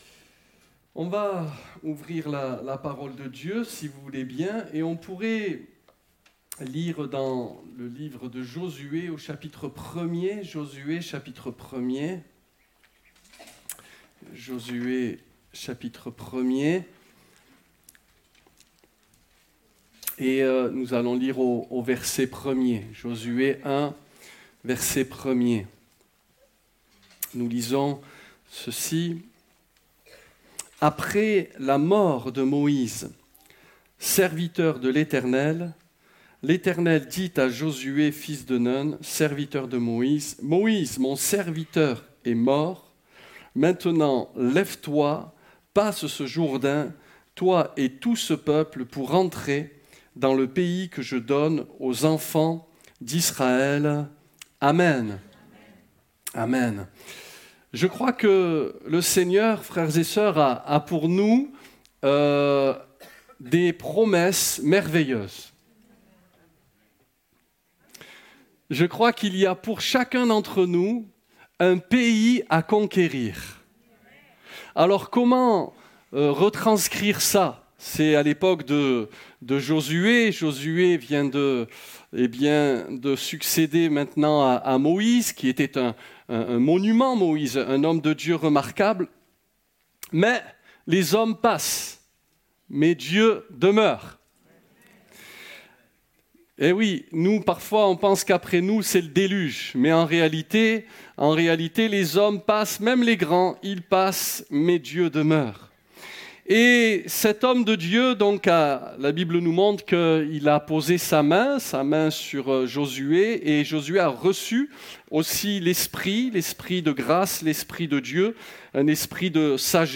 Passage: Josué 1 : 1-2 Réunion: Culte Culte du dimanche 24 octobre 2019